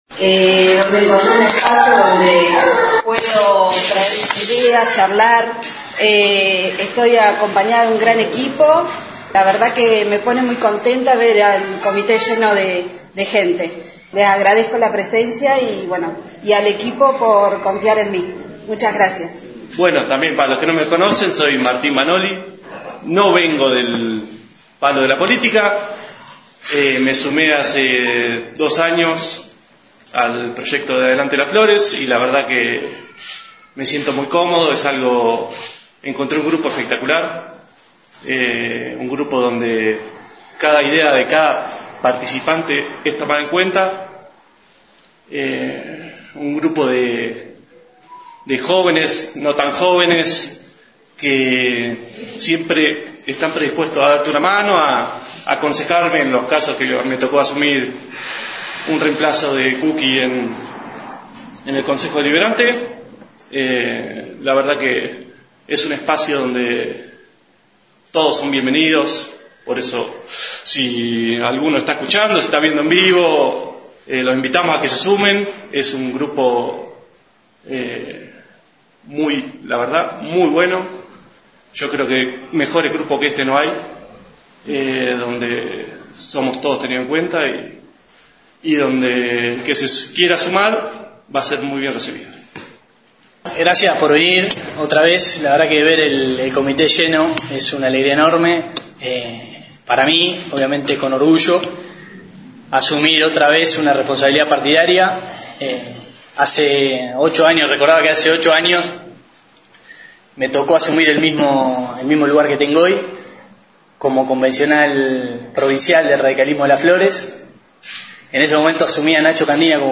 Hoy por la tarde en el local partidario de calle Hipólito Yrigoyen, asumieron las nuevas autoridades para la continuidad institucional.
acto-asuncion-de-autoridades-ucr.mp3